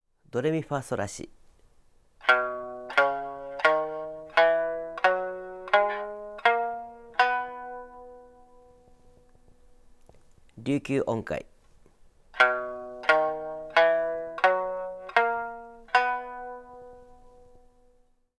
音声解説
琉球音階と西洋音階.wav